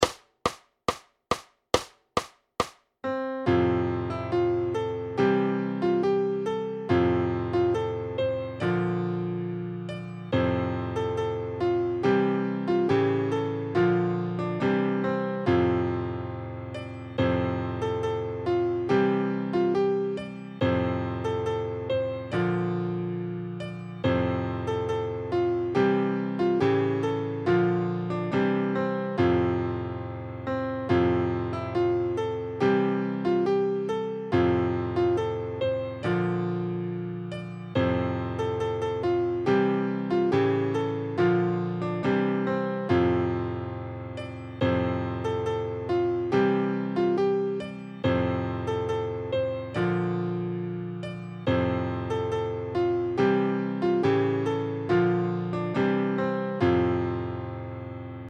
Noty na snadný klavír.
Ke všem písním jsou připojeny zvukové demonstrační ukázky.
Hudební žánr Folk